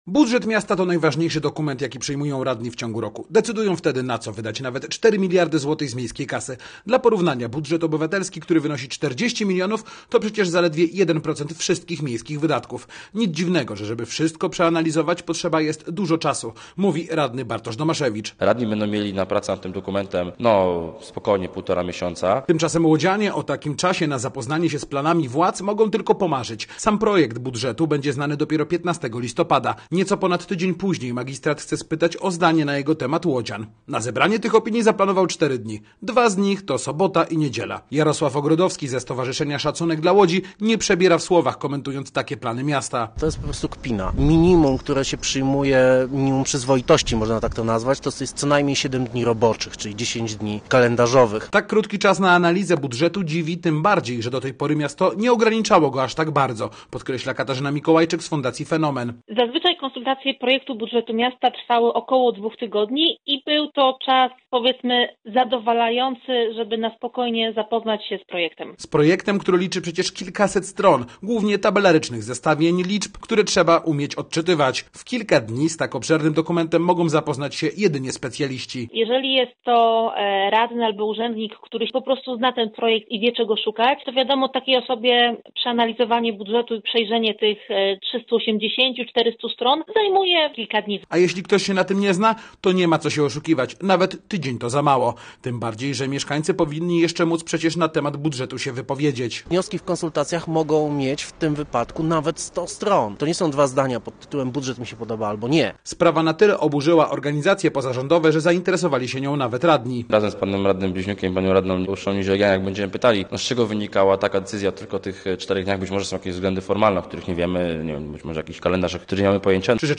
Posłuchaj relacji: Nazwa Plik Autor Krótkie konsultacje budżetowe audio (m4a) audio (oga) Projekt budżetu ma być znany 15 listopada.